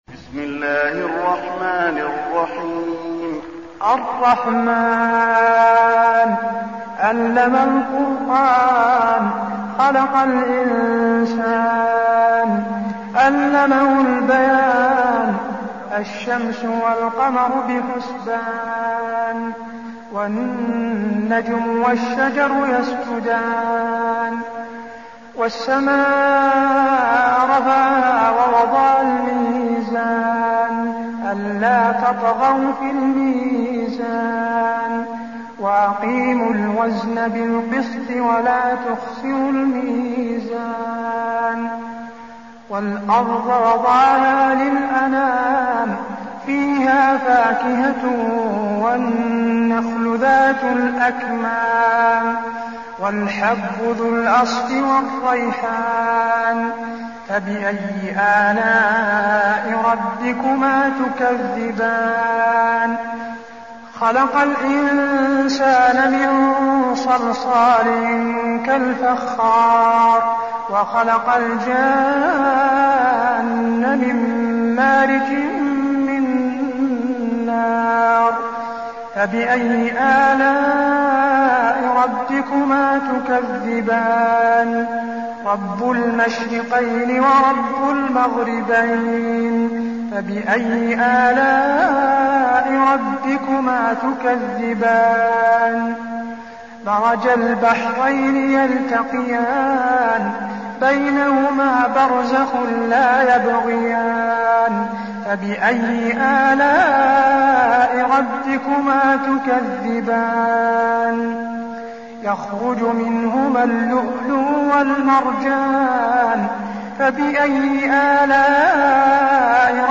المكان: المسجد النبوي الرحمن